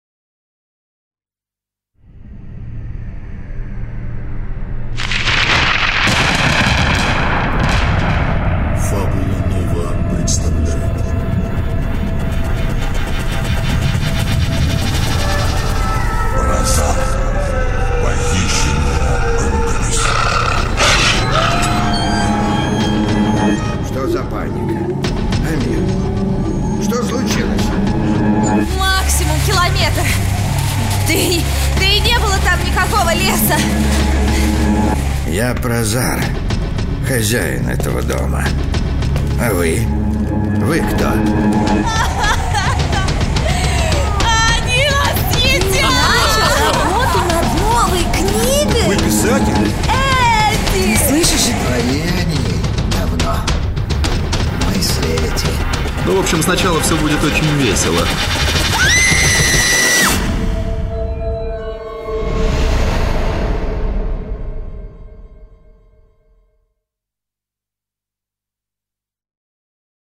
Аудиокнига Прозар. Похищенная рукопись | Библиотека аудиокниг
Похищенная рукопись Автор Игорь Орлов Читает аудиокнигу Актерский коллектив.